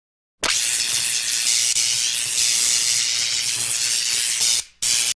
Electric shock sound - In - Bouton d'effet sonore